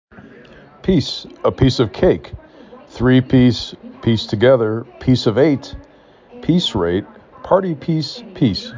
5 Letters, 1 Syllable
p E s